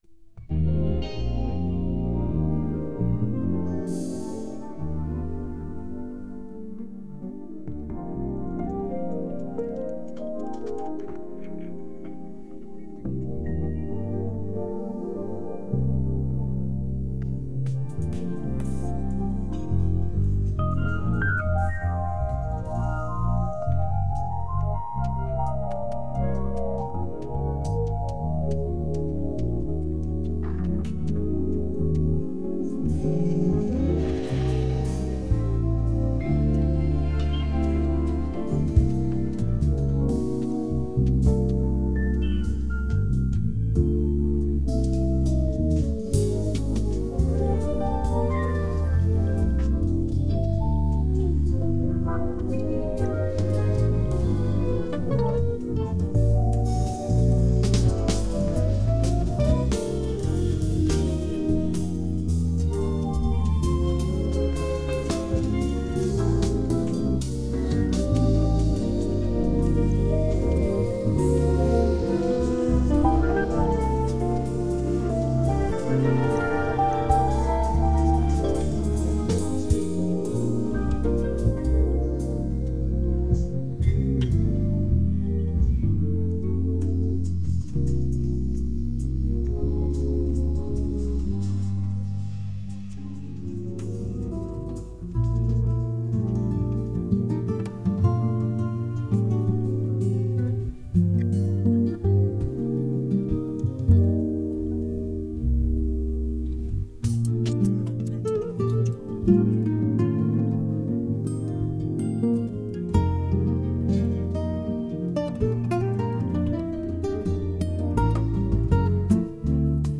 雑にくっつけて12分ぐらいにした。